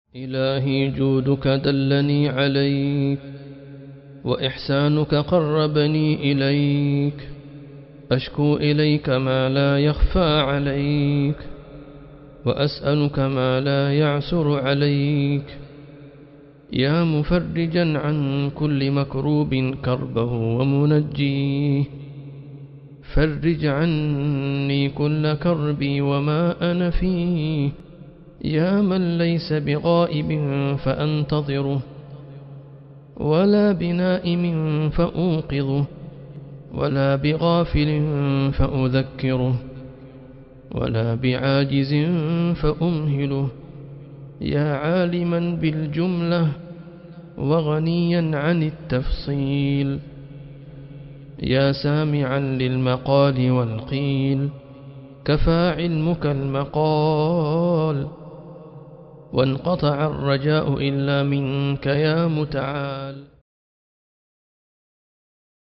مناجاة مؤثرة يتضرع فيها العبد إلى ربه، يشكو إليه همه وكربه ويسأله القرب والفرج. النص يسلط الضوء على سعة علم الله وقدرته، ويقطع رجاء المتضرع عن كل شيء سواه.